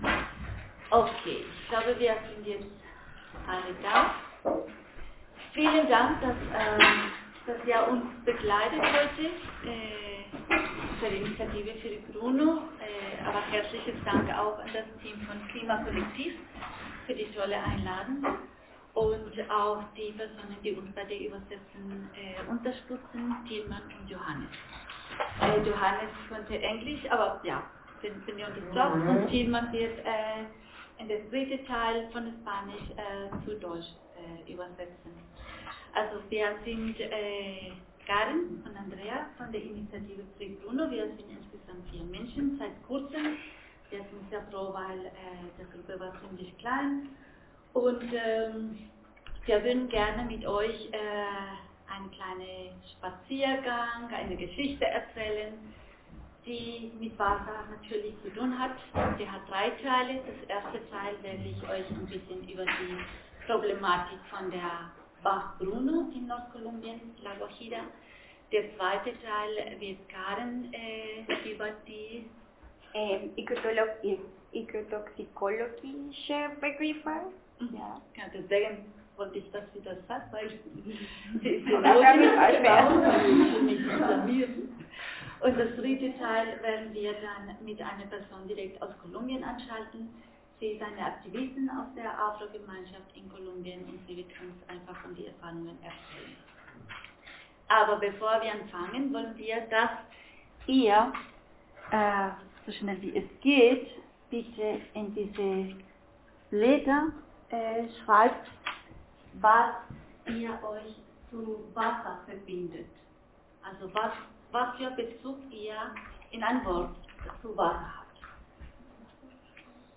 Wasserkonferenz: Workshop Widerstand und Verteidigung des Rechts auf Wasser in La Guajira, Kolumbien 22/03/2025